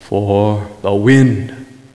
forWind2.WAV